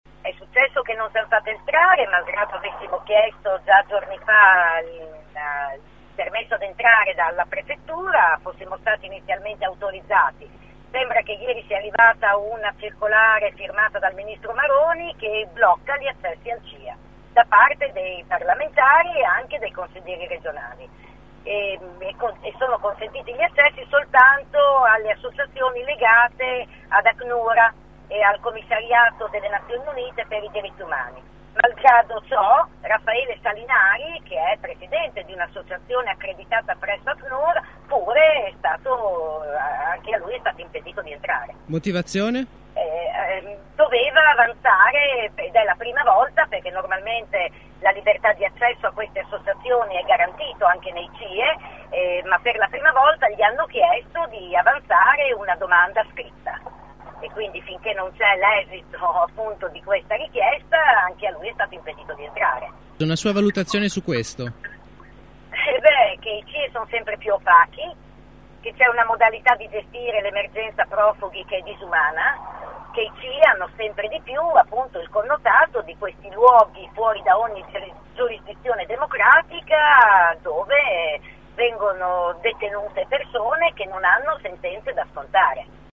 Ascolta Monica Donini sul divieto di ingresso